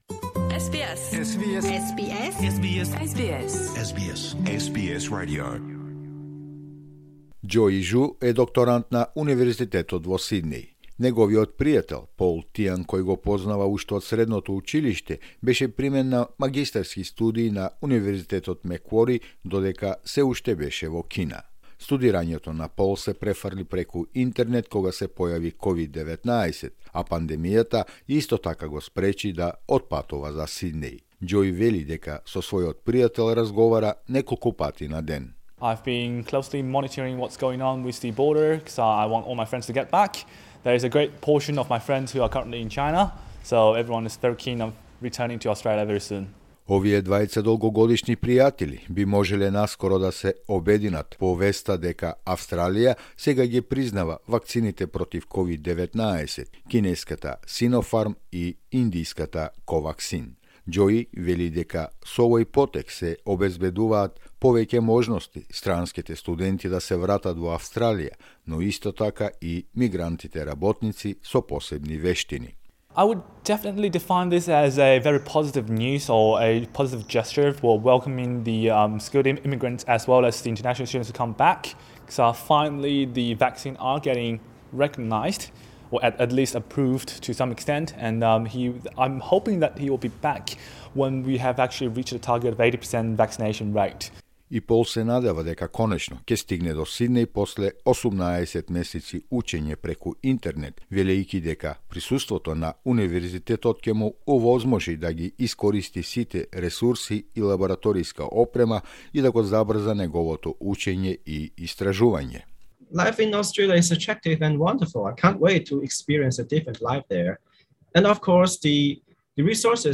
Australia's Therapeutic Goods Administration [[T-G-A]] has officially recognised two additional vaccines for inoculation against COVID-19 - China's Sinopharm [[SY-no-farm]] and India's Covaxin. Their approval spells out relief for thousands of fully vaccinated students, citizens and family members waiting to come to Australia.